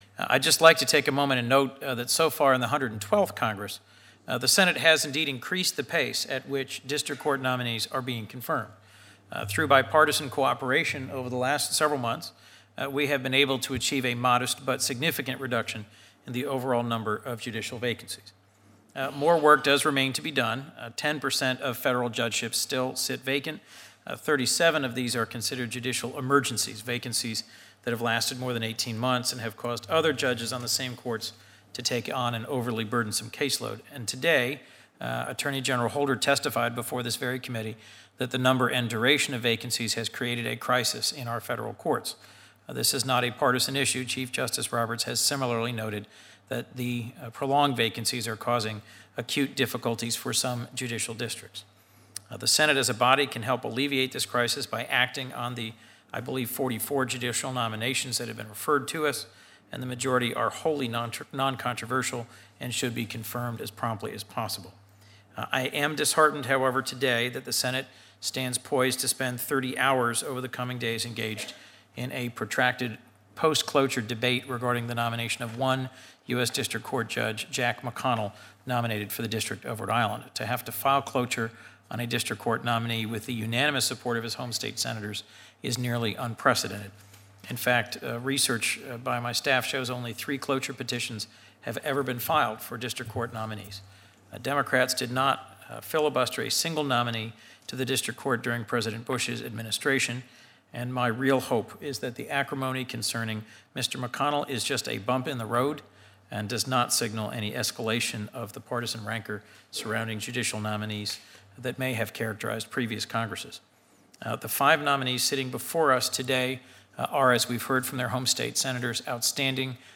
Asked to chair this afternoon’s Judiciary Committee hearing on five nominees to the federal bench, Senator Coons took the opportunity to urge his colleagues to move more quickly on judicial confirmations.